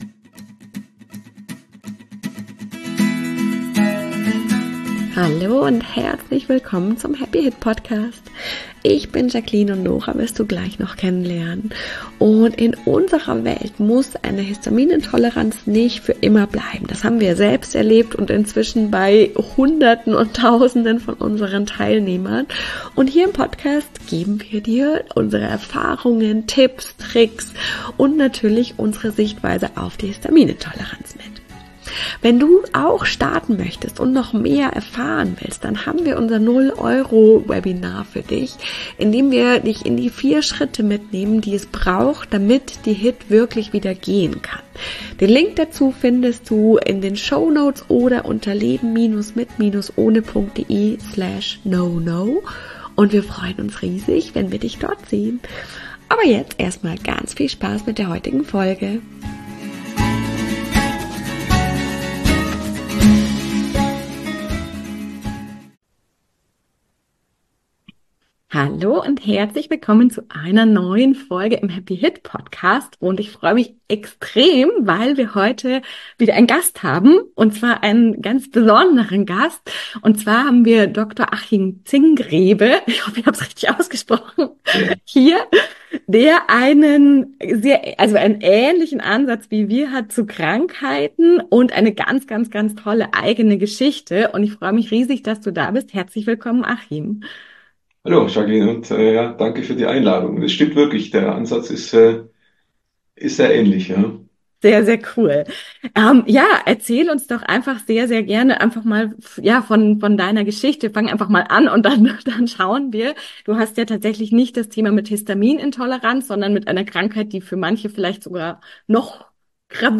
#95 [Interview] Krebs Meistern